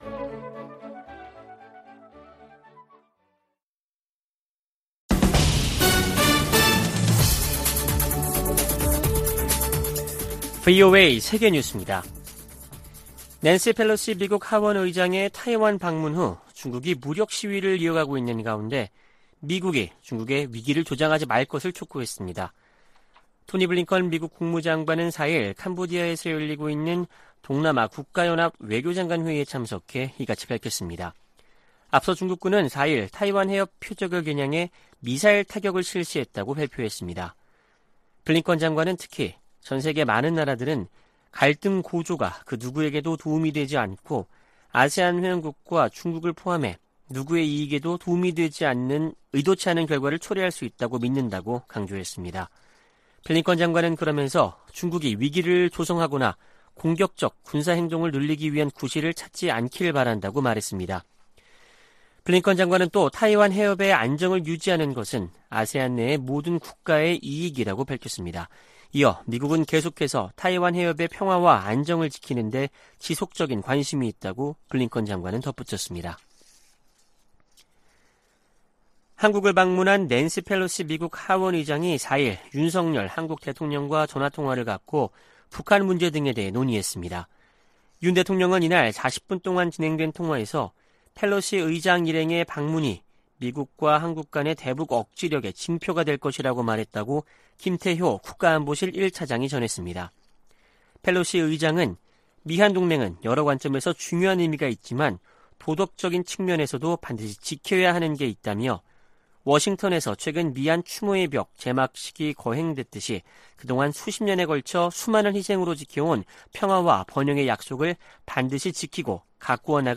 VOA 한국어 아침 뉴스 프로그램 '워싱턴 뉴스 광장' 2022년 8월 5일 방송입니다. 윤석열 한국 대통령이 낸시 펠로시 미 하원의장의 방한 행보에 관해 미한 대북 억지력의 징표라고 말했습니다. 미국은 한국에 대한 확장억제 공약에 매우 진지하며, 북한이 대화를 거부하고 있지만 비핵화 노력을 계속할 것이라고 국무부가 강조했습니다. 미국과 한국의 합참의장이 화상대화를 갖고 동맹과 군사협력 등에 관해 논의했습니다.